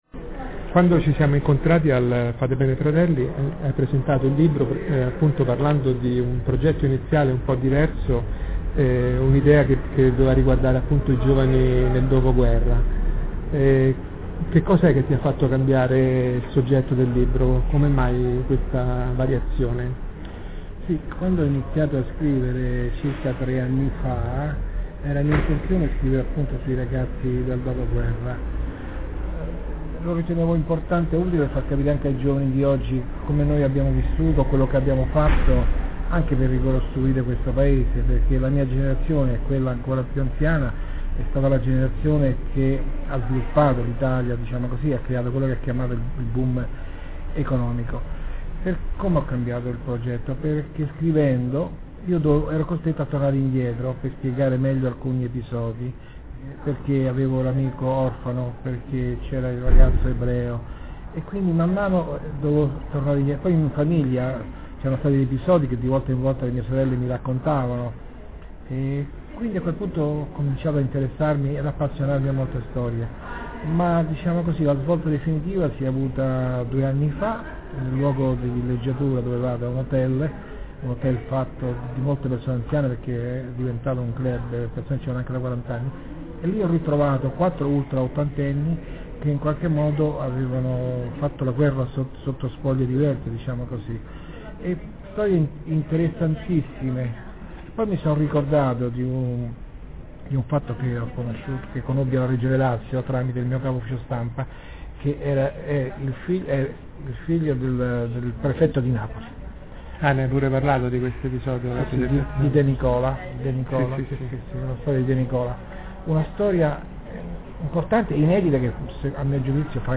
Recensione ed intervista